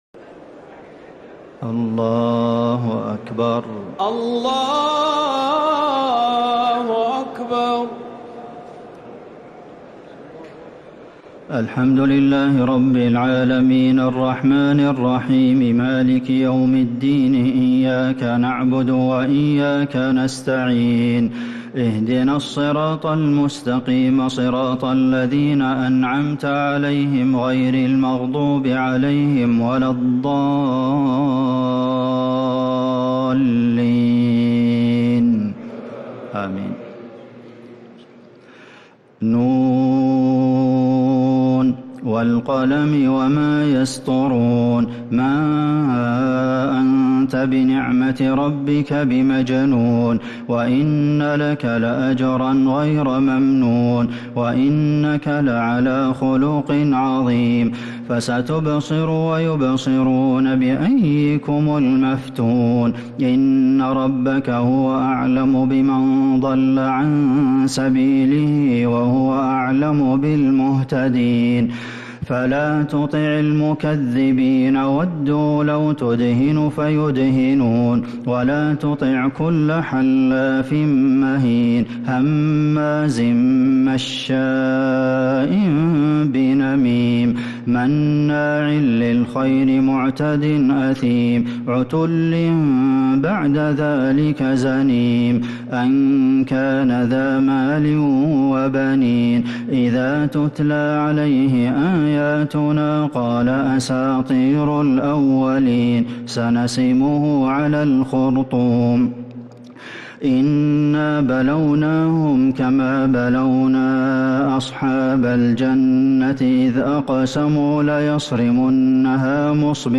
تراويح ليلة 28 رمضان 1446هـ من سورة القلم إلى سورة المعارج | Taraweeh 28th night Ramadan 1446H Surah Al-Qalam to Al-Ma'arij > تراويح الحرم النبوي عام 1446 🕌 > التراويح - تلاوات الحرمين